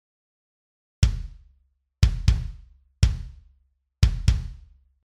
バスドラム
一番大きな太鼓で、足でペダルを踏んで鳴らします。
bassdrum.mp3